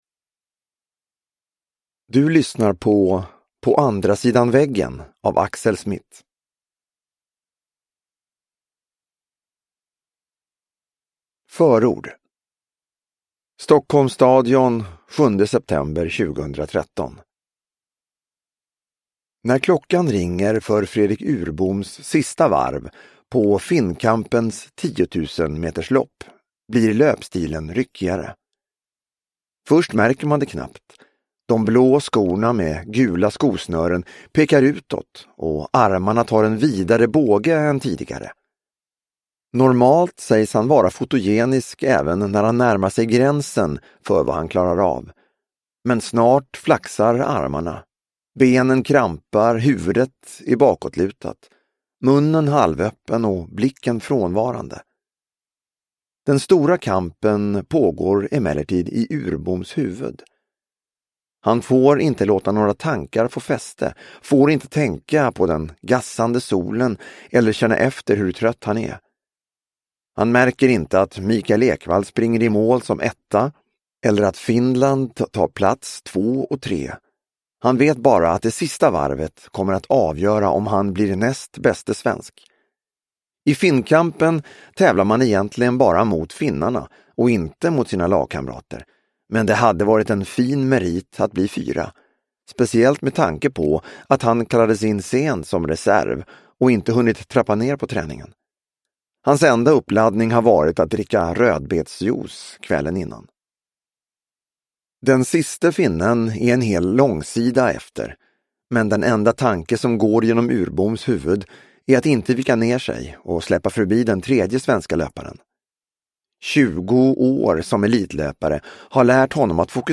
På andra sidan väggen – Ljudbok – Laddas ner